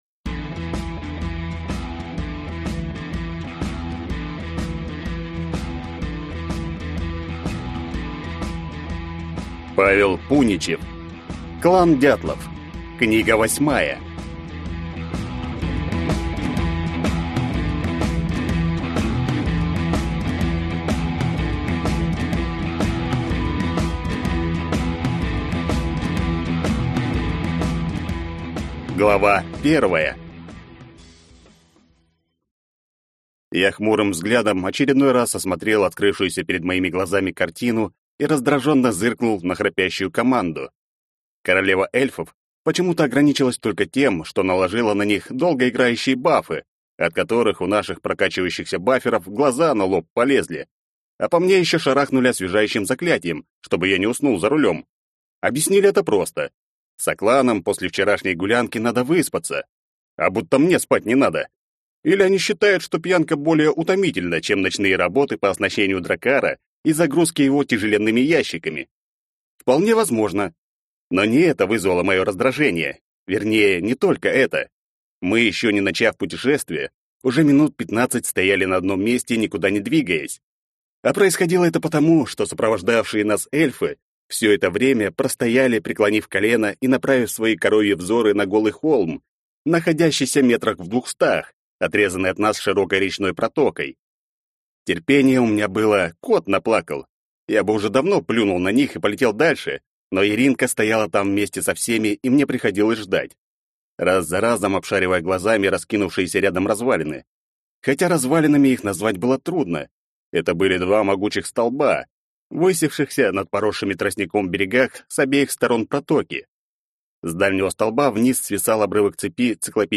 Аудиокнига Клан «Дятлов». Книга 8 | Библиотека аудиокниг